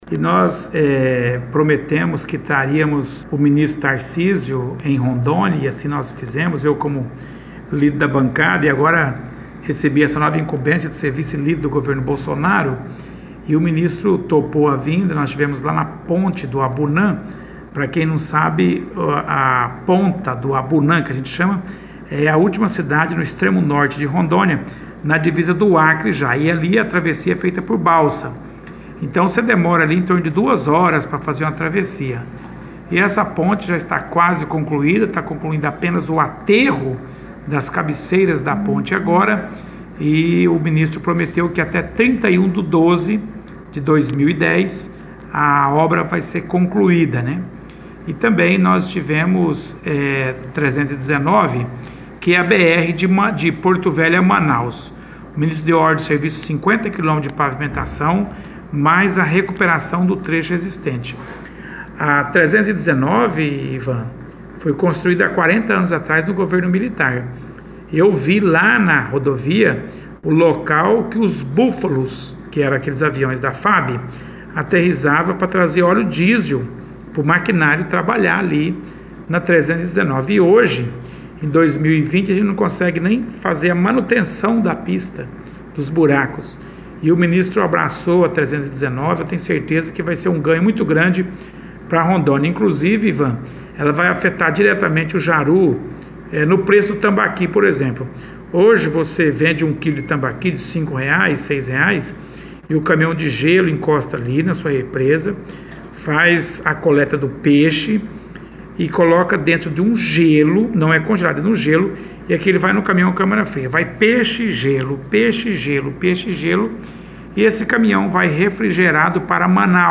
Entrevista foi ao ar nesta terça-feira, no Microfone Aberto.
O deputado federal Lucio Mosquini (MDB), líder da bancada federal em Brasília e vice-líder do governo federal no Congresso Nacional, cedeu entrevista para a Rede Massa FM de rádio nesta terça-feira (6) e lembrou da importância da BR-319, para o Estado e para Jaru (RO), em especial.